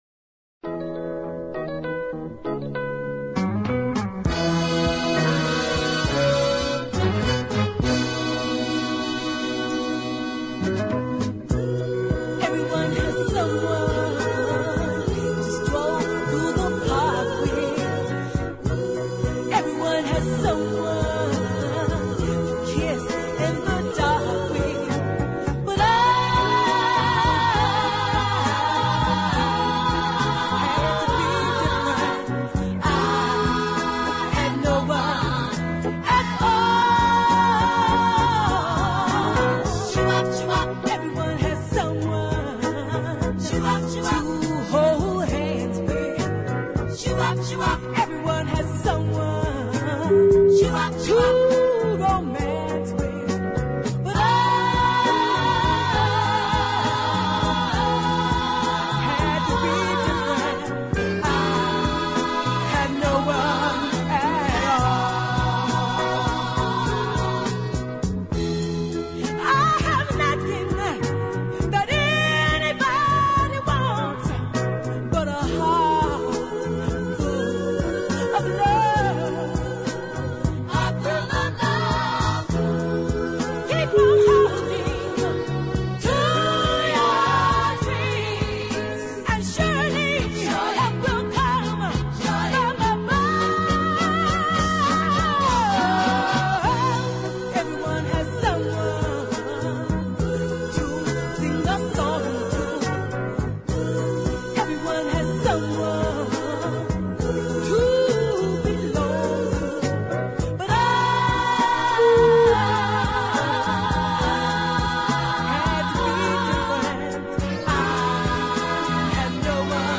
gospel tinged wailing. Really fine singing.